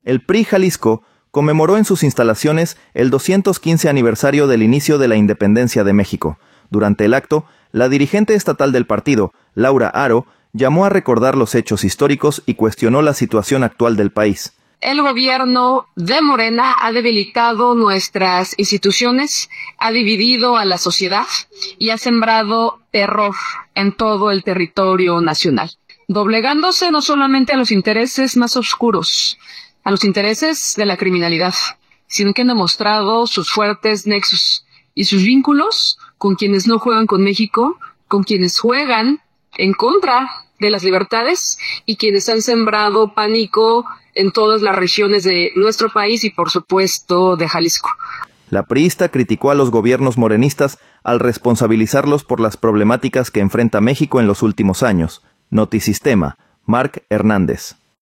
El PRI Jalisco conmemoró en sus instalaciones el 215 aniversario del inicio de la Independencia de México. Durante el acto, la dirigente estatal del partido, Laura Haro, llamó a recordar los hechos históricos y cuestionó la situación actual del país.